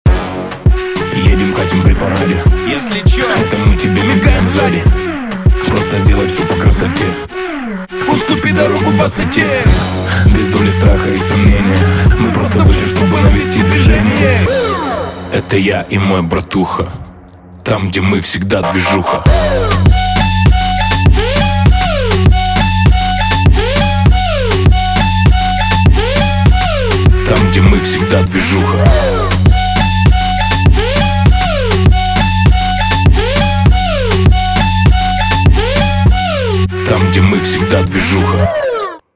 • Качество: 320, Stereo
громкие
Moombahton
полицейская сирена